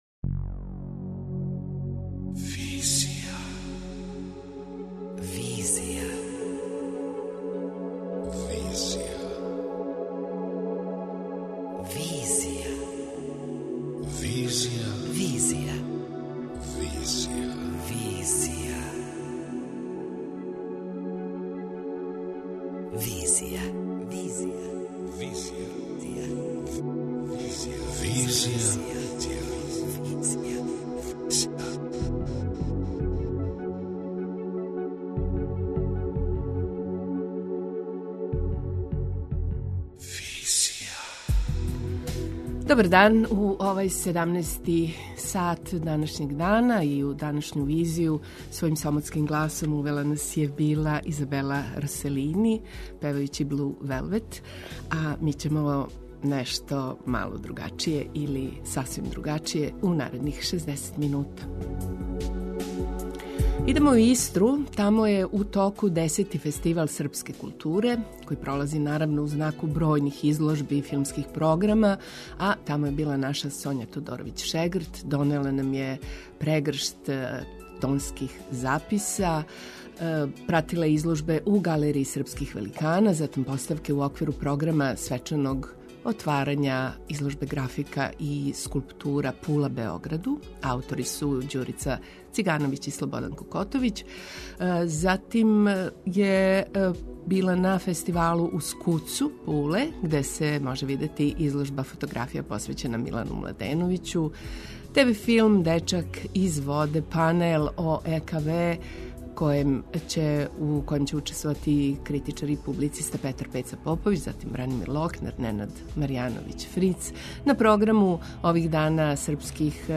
Ово је била јединствена прилика за документарну репортажу о Српском културном центру у Пули и о приказу живота и културе Срба у Истри.